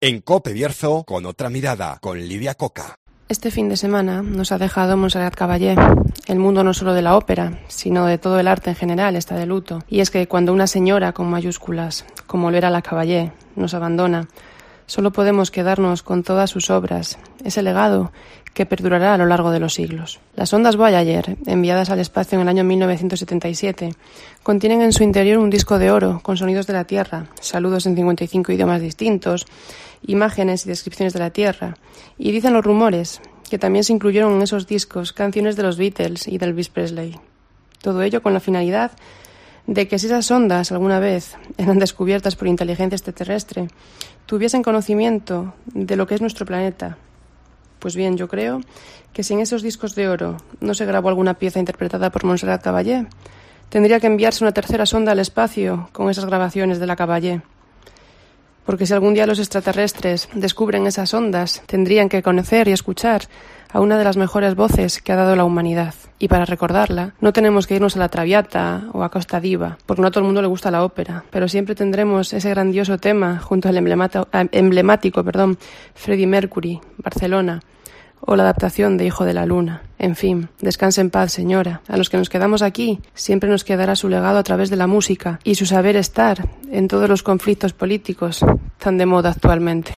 OPINIÓN